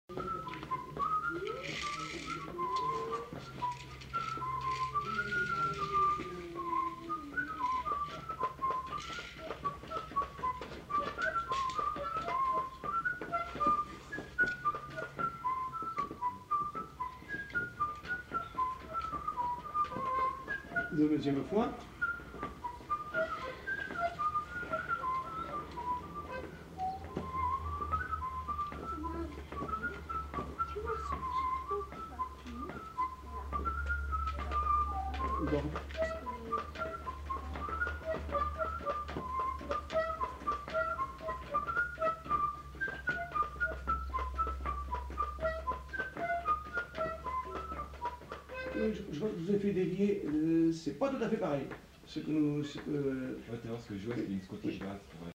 Aire culturelle : Agenais
Lieu : Foulayronnes
Genre : chant
Effectif : 1
Type de voix : voix d'homme
Production du son : sifflé
Danse : bourrée